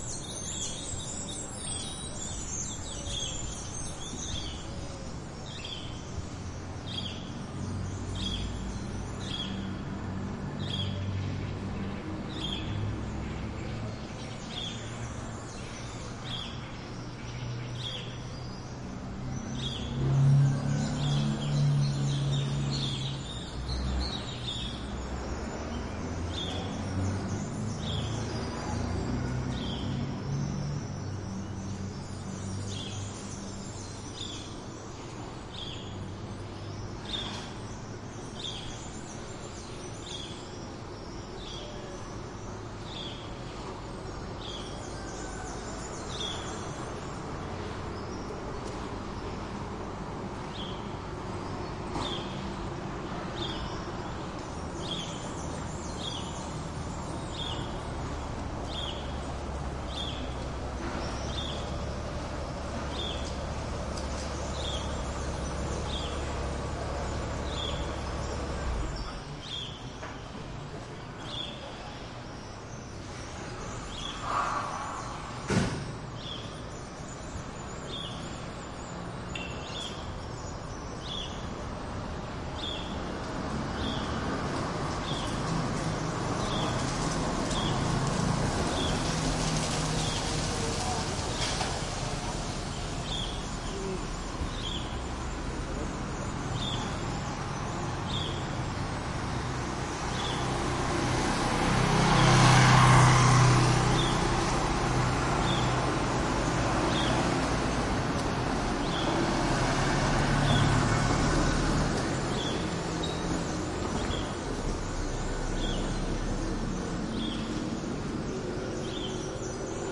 哥伦比亚 " 小镇或安静的城市清晨的天际线灯光机器嗡嗡声鸟儿轻快的脚步和远处的道路交通+一些
描述：小镇或安静的城市清晨天际线光机嗡嗡声鸟光步骤和遥远的道路交通+一些嘶哑的汽车摩托车通过关闭，教堂的钟声中间的家伙说话左和扫地右Saravena，哥伦比亚2016
Tag: 西班牙语 天际线 早晨 安静